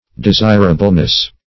Desirableness \De*sir"a*ble*ness\, n.